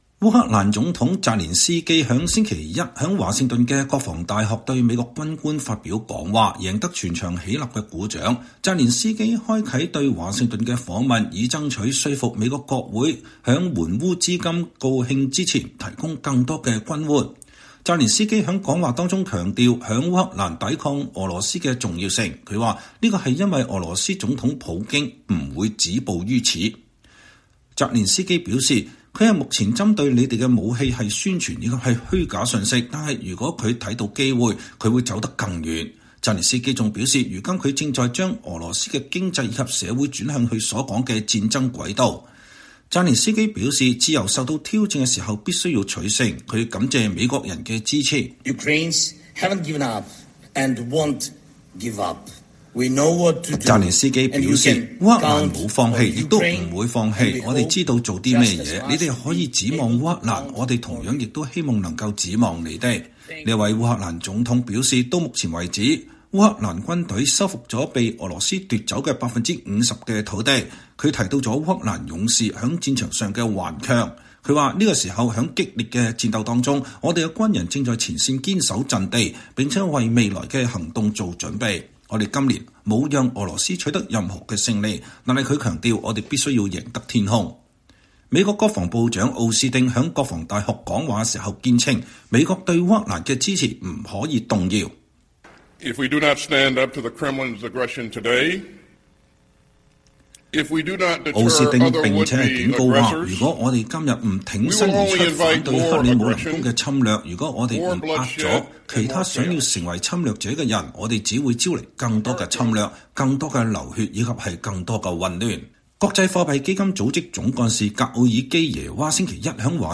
澤連斯基在華盛頓國防大學對美國軍官發表演講
烏克蘭總統澤連斯基(Volodymyr Zelenskyy)週一(12月11日)在華盛頓的國防大學對美國軍官發表講話，贏得全場起立鼓掌。澤連斯基開啟了對華盛頓的訪問，以爭取說服美國國會在援烏資金告罄之前提供更多軍援。